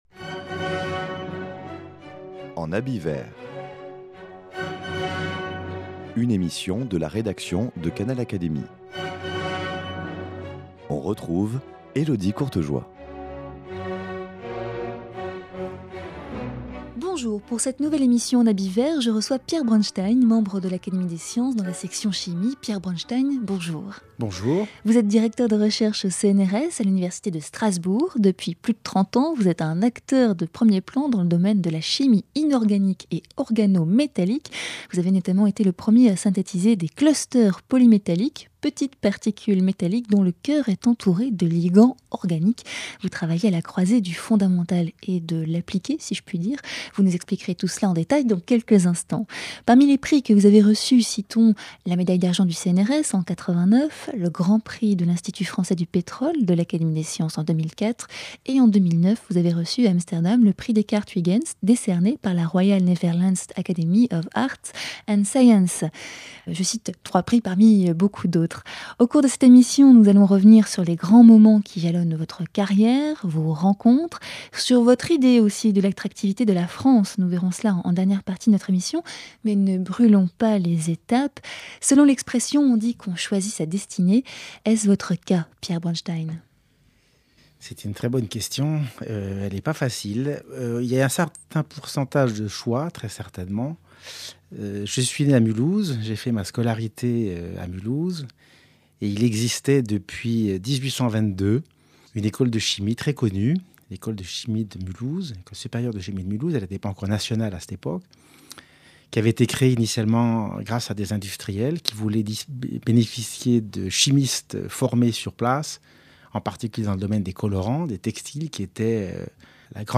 Il a notamment été l’un des pionniers dans la synthèse des clusters polymétalliques, molécules dont le cœur métallique est entouré de ligands organiques. Rencontre avec l’académicien pour évoquer ses découvertes, ses rencontres et son implication dans la fondation Kastler qui accueille les chercheurs étrangers en France.